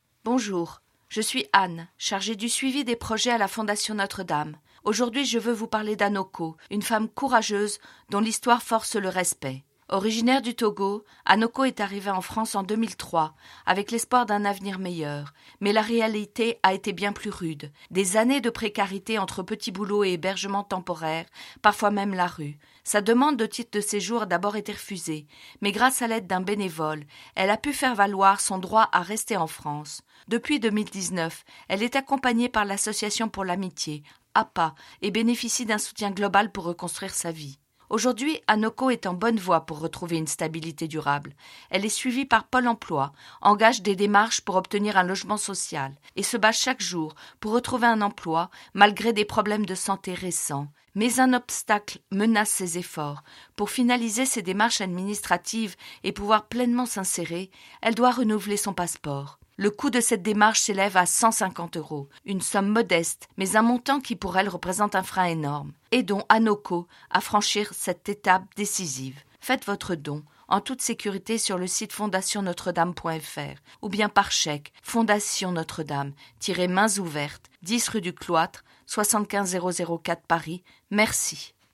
Messages « Mains Ouvertes » sur Radio Notre Dame